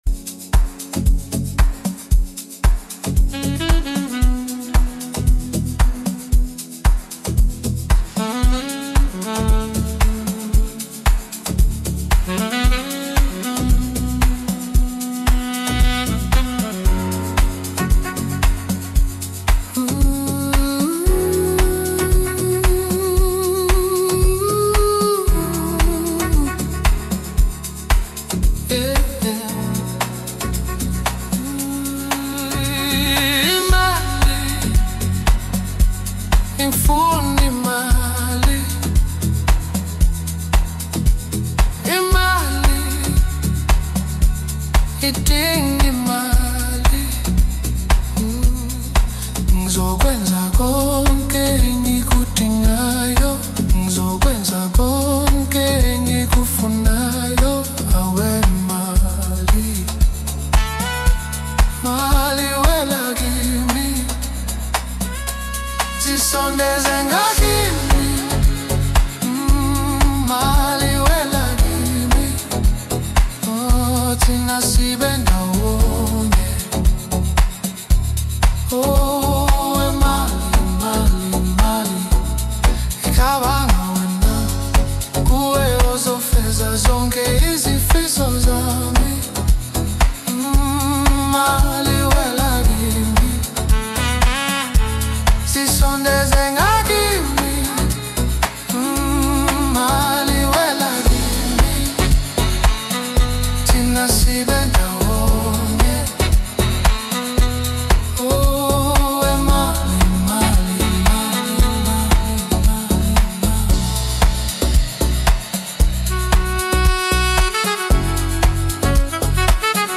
Home » Amapiano
South African singer-songwriter